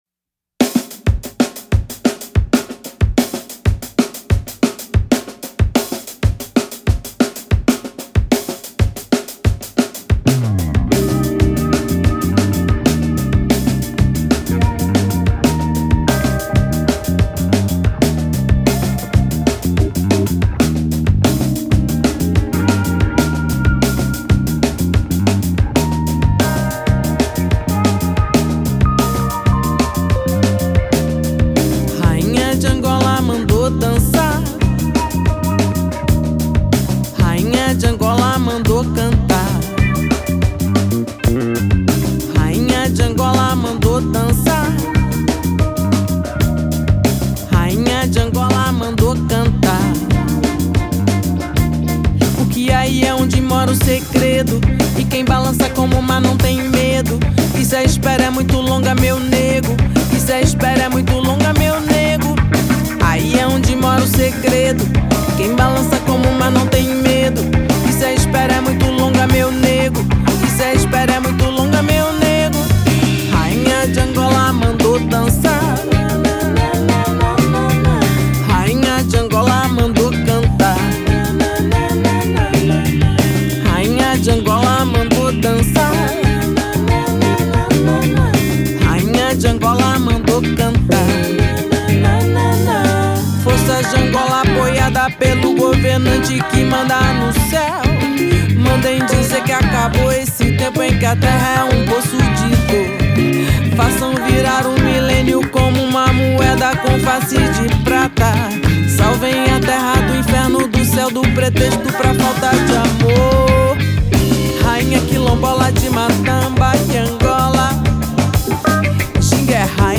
drums
bass
guitar